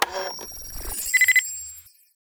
can calibrate.wav